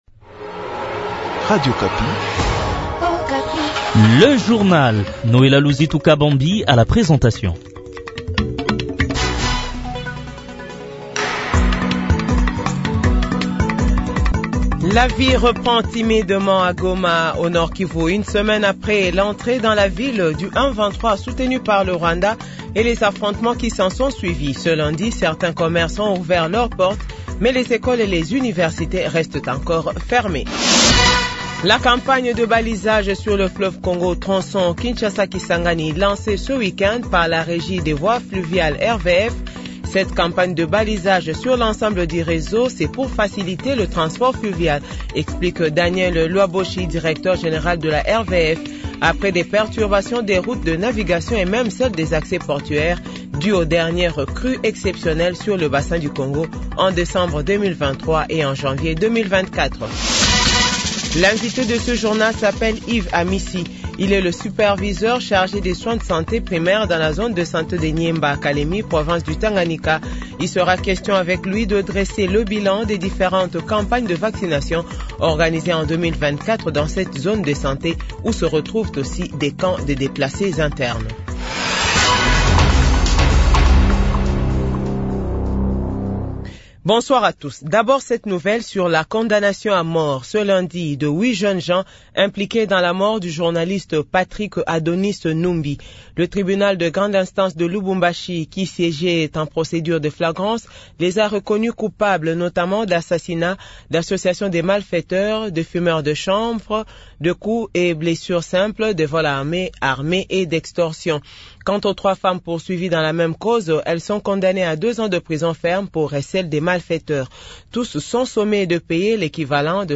Journal 18h00